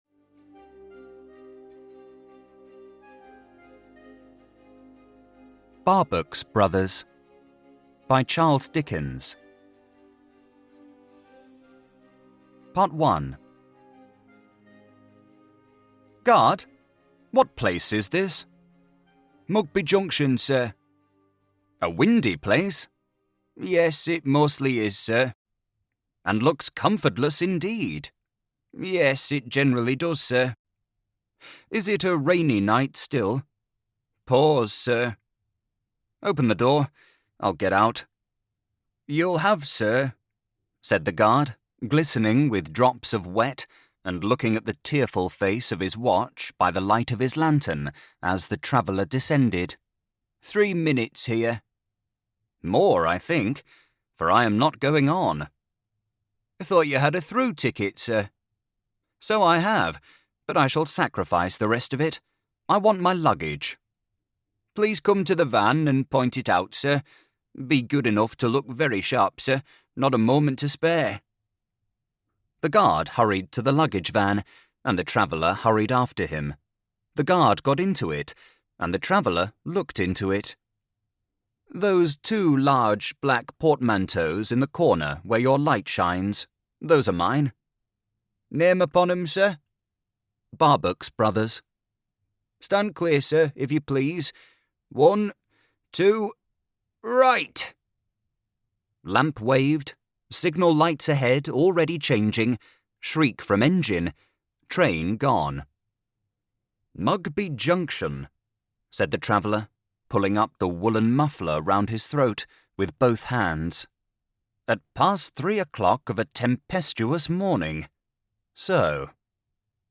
Barbox Brothers by Charles Dickens - Classic Short Stories Audiobook